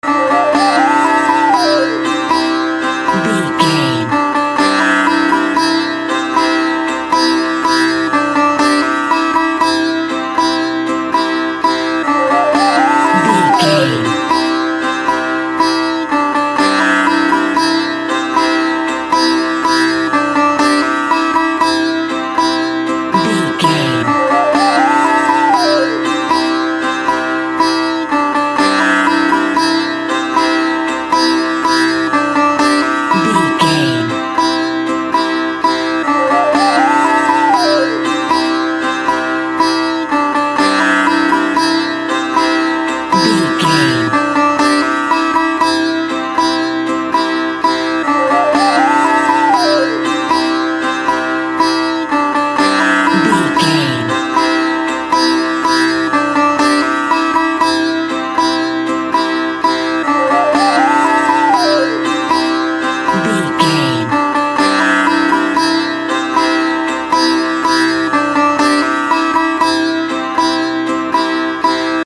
Ionian/Major
World Music
ethnic music